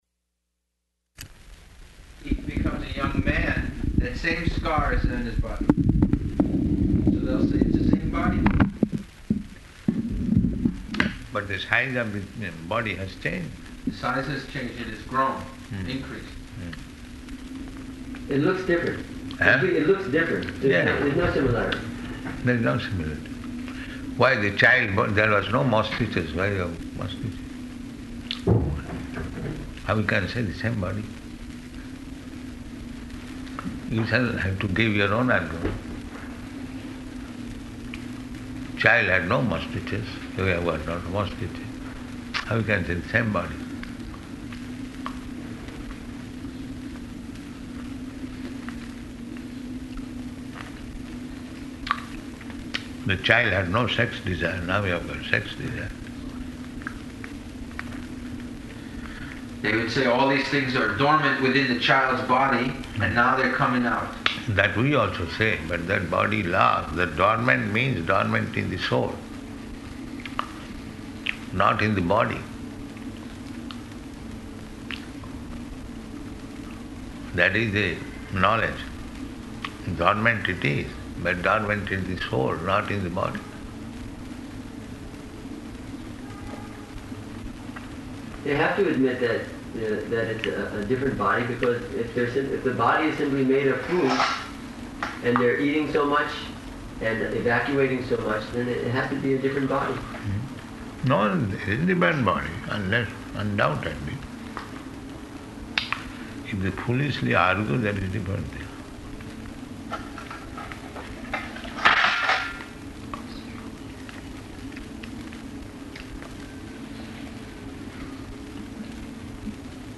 Room Conversation
Type: Conversation
Location: Mauritius
[Prabhupāda is taking breakfast prasādam ]